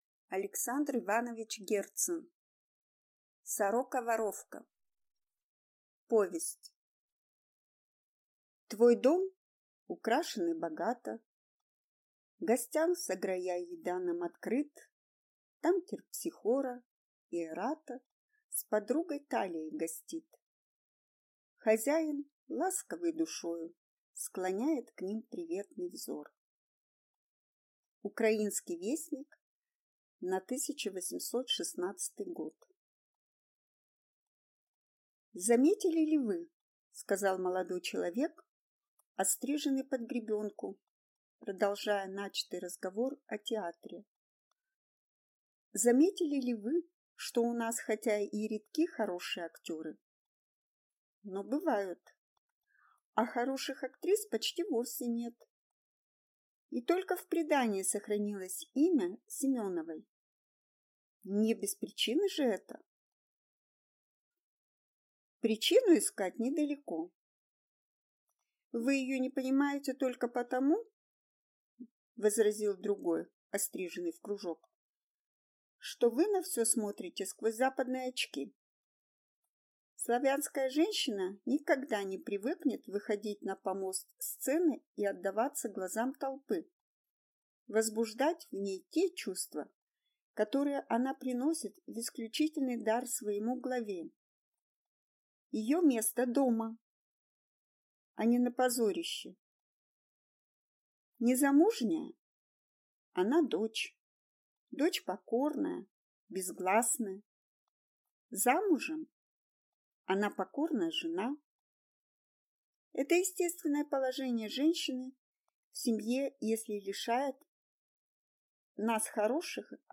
Аудиокнига Сорока-воровка | Библиотека аудиокниг